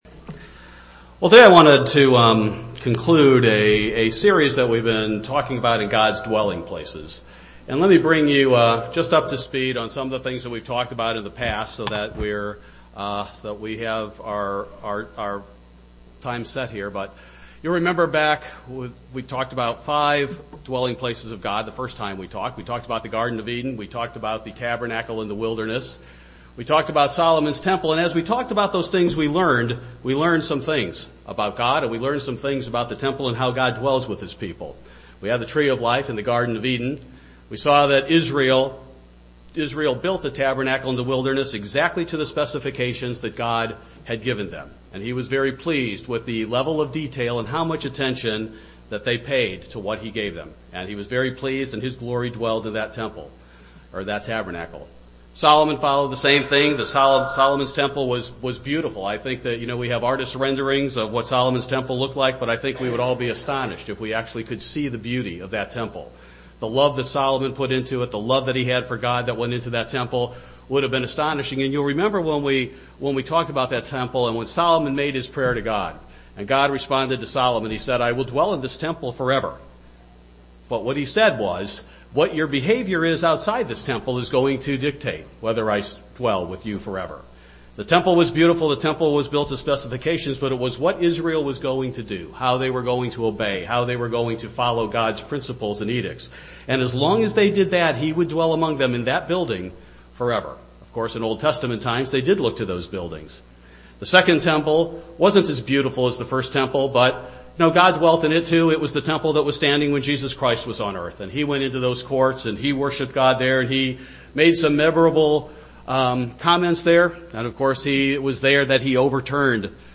Part 3 of a three-part sermon series on where God dwells.